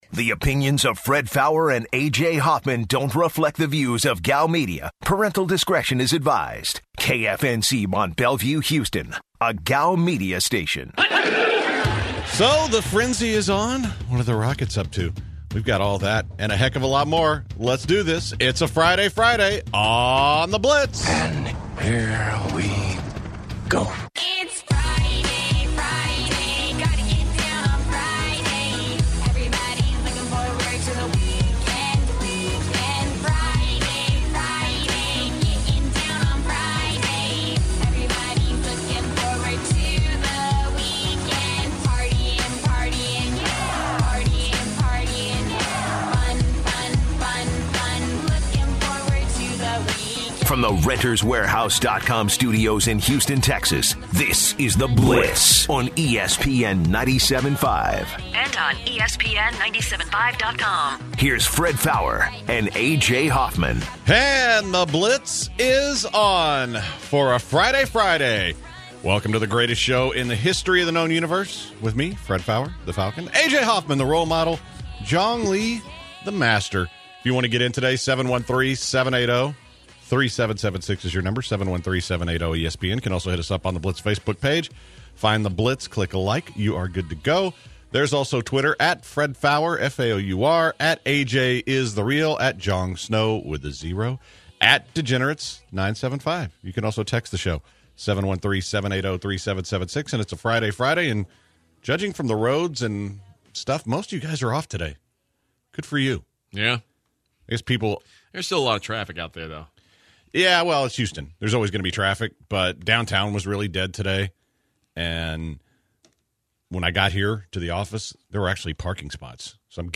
Later in the show, the guys take a couple of listener phone calls and touch up on Boxer Canelo Alvarez and his next fight, and college football.